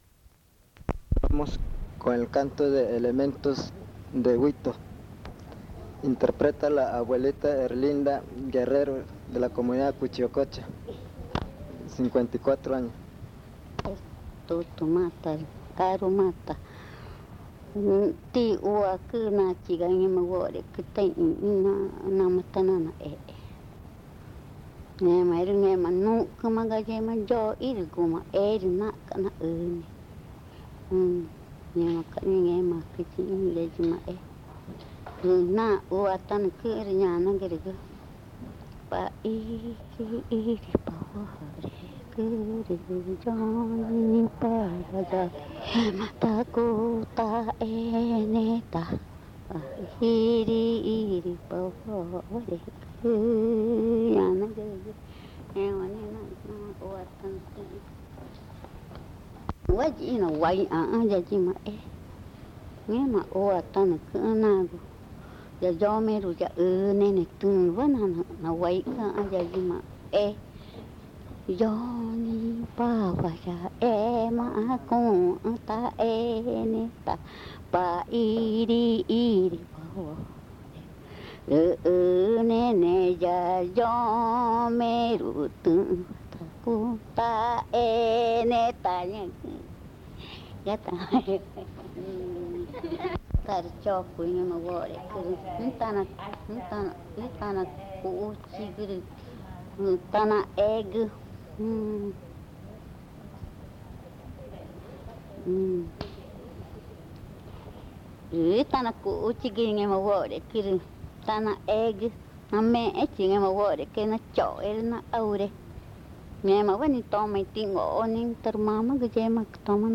Pozo Redondo, Amazonas (Colombia)